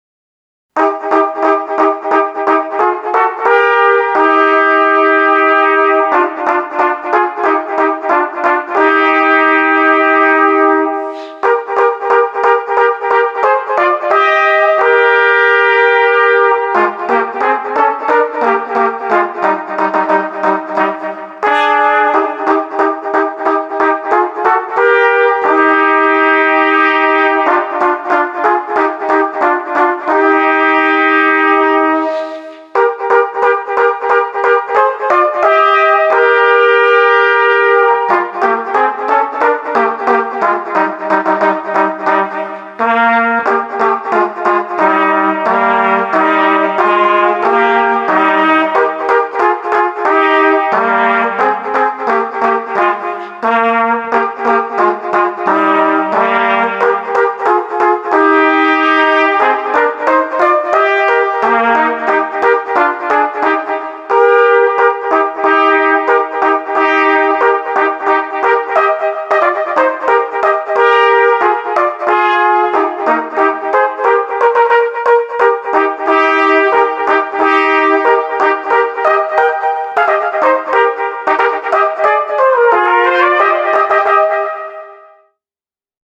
Gattung: für zwei Trompeten oder Flügelhörner